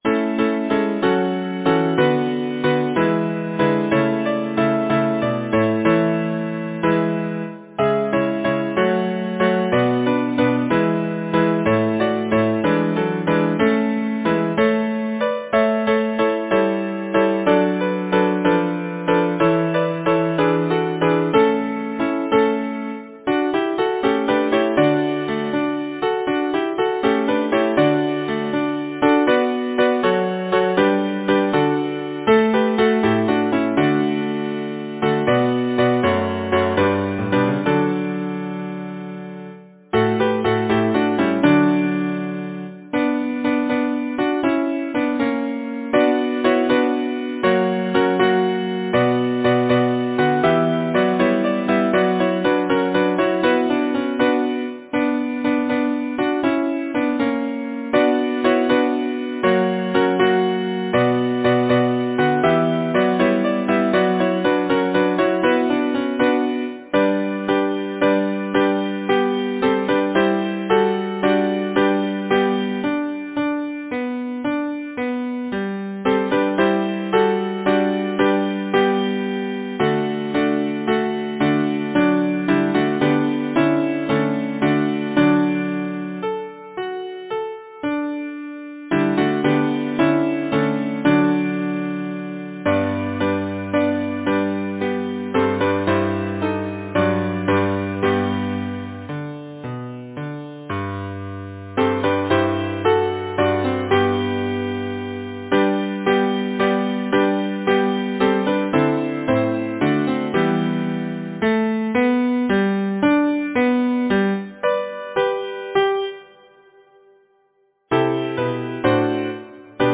Title: The Nursery Composer: Alfred Moffat Lyricist: Number of voices: 4vv Voicing: SATB Genre: Secular, Partsong, Nursery rhyme, Humorous song
Language: English Instruments: A cappella